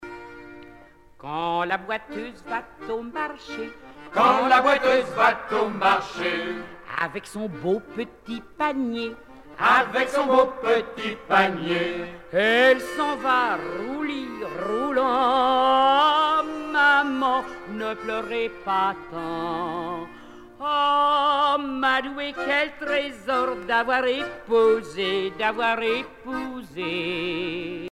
laisse
Chants de haute mer
Pièce musicale éditée